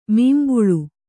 ♪ mīmbulu